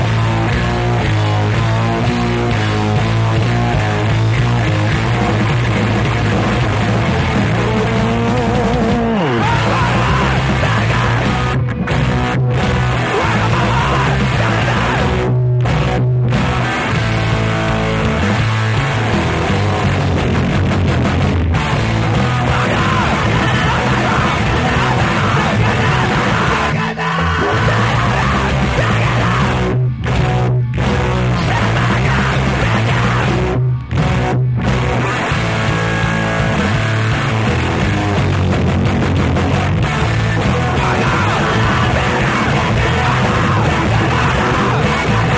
625 THRASHCORE
BASS
DRUMS
GUITAR
VOCALS